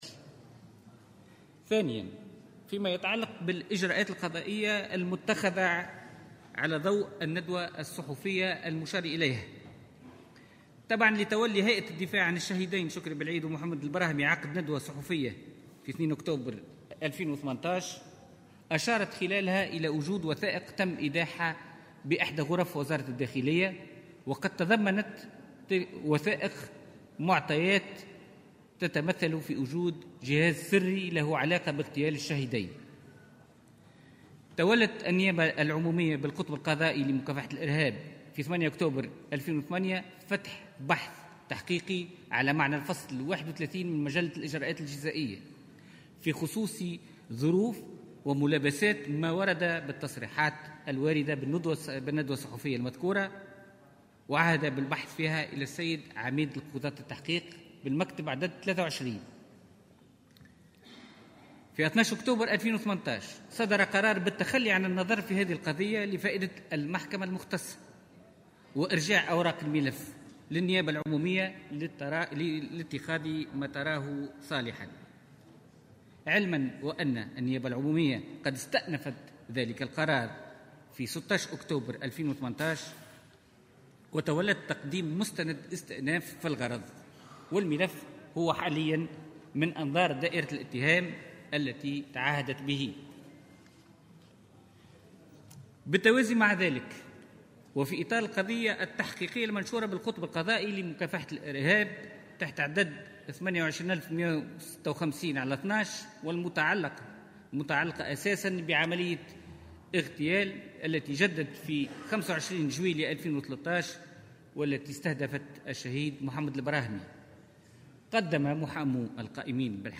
كشف وزير العدل خلال جلسة المساءلة التي يعقدها البرلمان اليوم على ضوء معطيات...